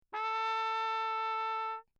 13 Spitzdämpfer (Straight Mutes) für Trompeten im Klangvergleich
Im Rahmen dieser Arbeit wurden verschiedene kurze Sequenzen zunächst ohne Dämpfer und dann mit dreizehn verschiedener Spitzdämpfer im reflexionsarmen Raum der mdw aufgenommen.
Drehventiltrompete
Ton h1
TRP-MUTE_Lechner_CrownMe_h1.mp3